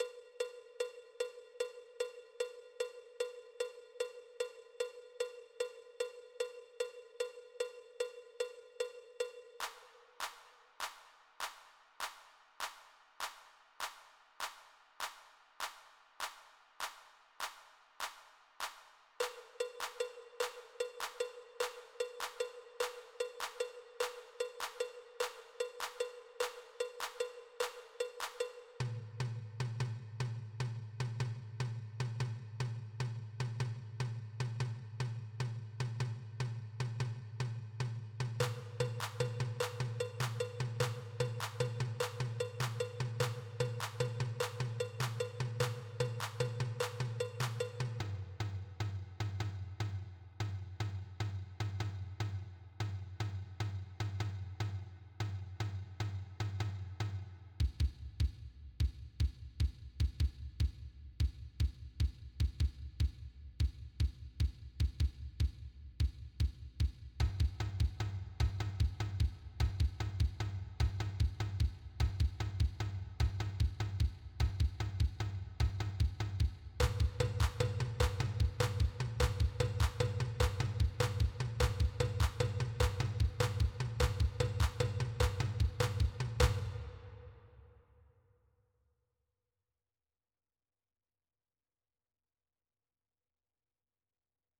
West African Polyrhythms (mp3) - faster
West African Polyrhythms 300.mp3